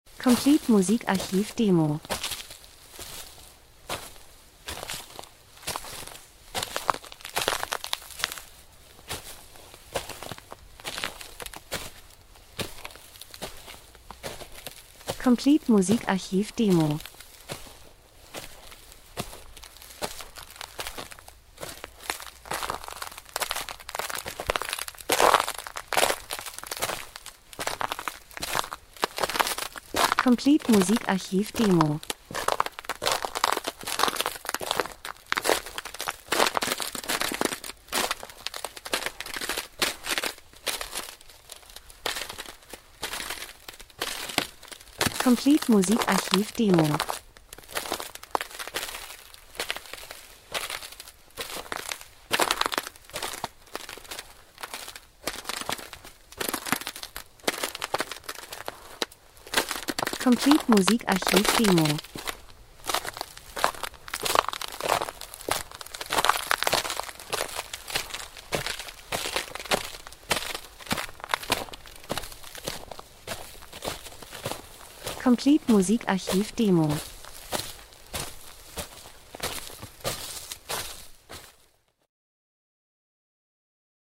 Winter - Spaziergang durch gefrorenen Schnee Eis 01:23